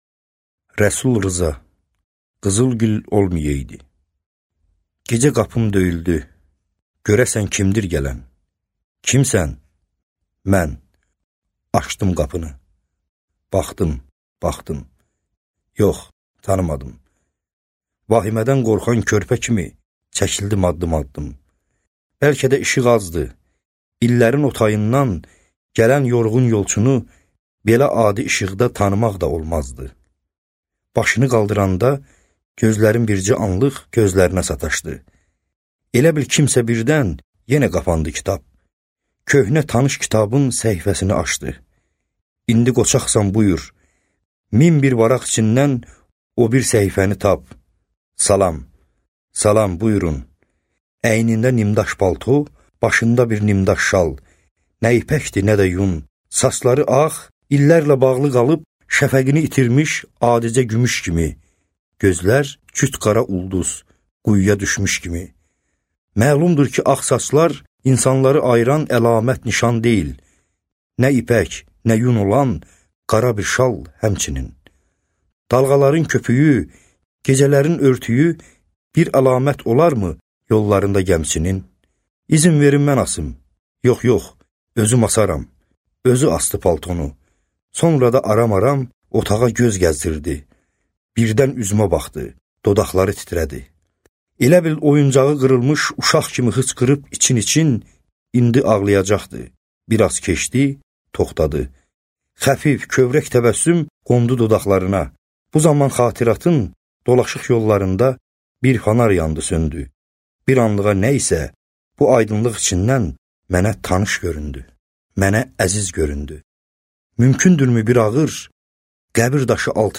Аудиокнига Qızıl gül olmayaydı | Библиотека аудиокниг